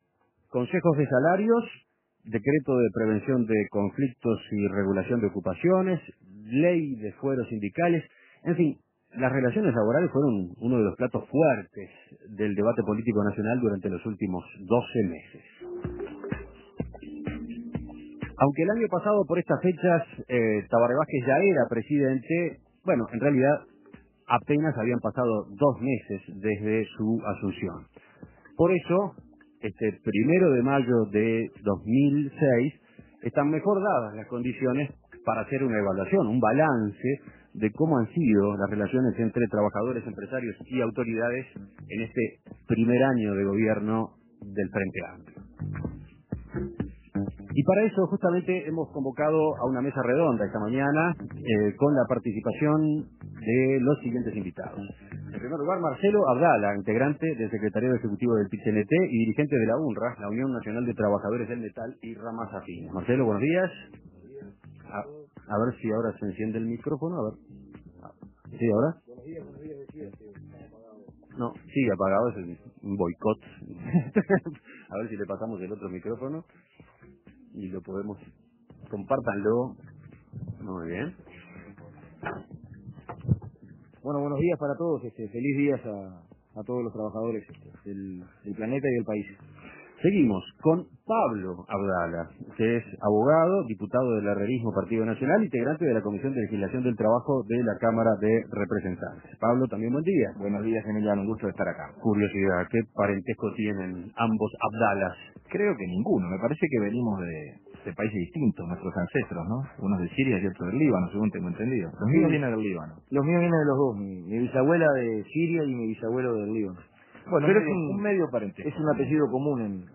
Escuche la mesa redonda sobre las relaciones laborales ante el segundo 1 de Mayo de la administración del Frente Amplio